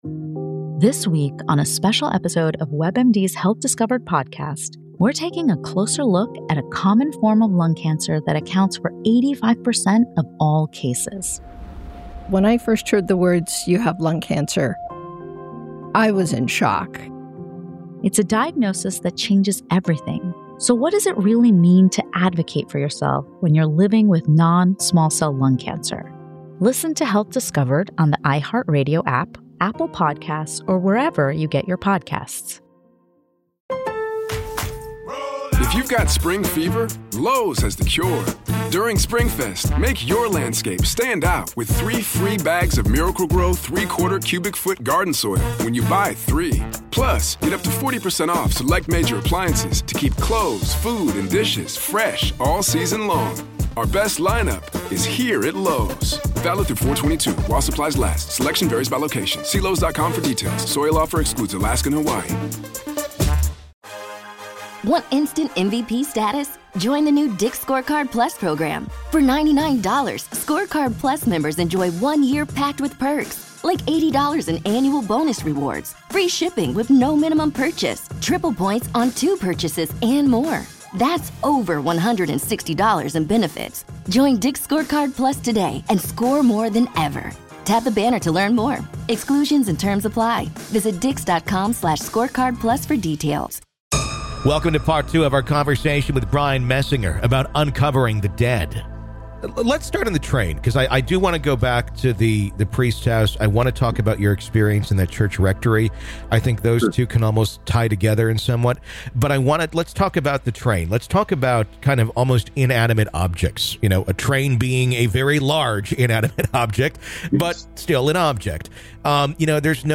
This episode explores what it’s like to grow up knowing the dead are real—and how living alongside them can shape the rest of your life. This is Part Two of our conversation.